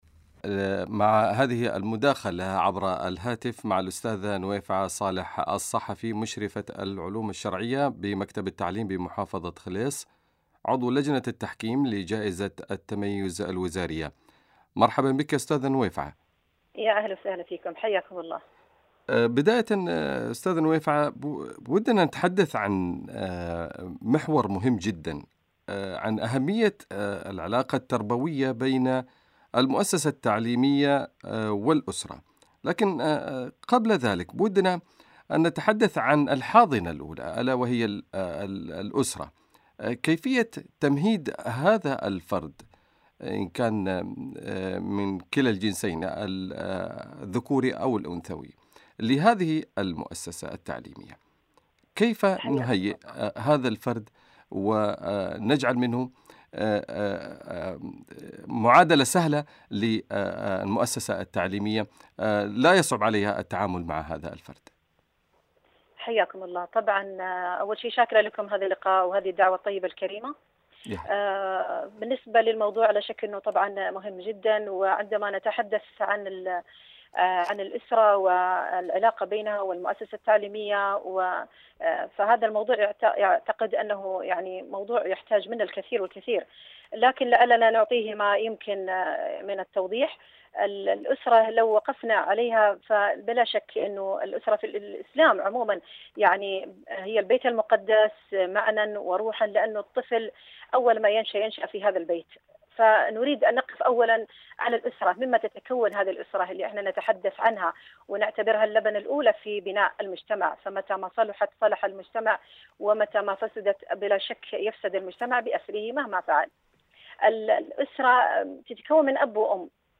في حوار ثري وغني بالمعلومات المفيدة والتوجيهات السديدة على مدار ثلاثين دقيقة